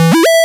retro_synth_beeps_07.wav